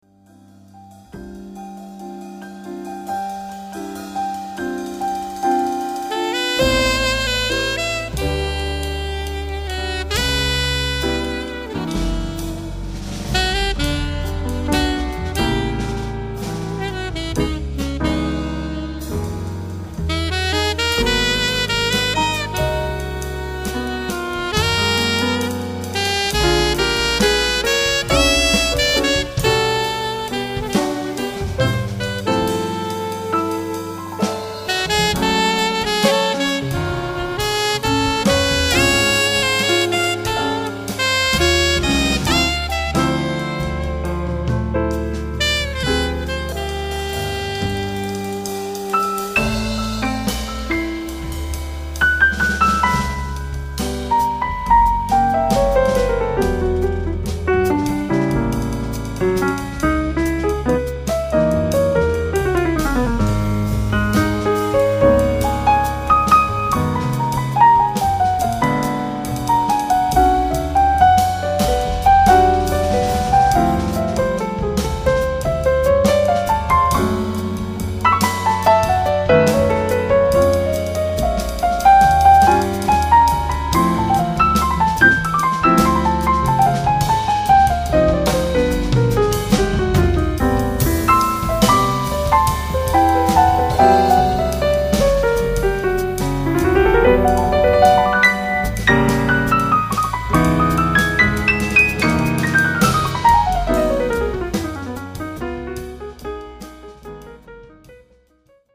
sax alto
piano, piano elettrico
contrabbasso, basso elettrico
batteria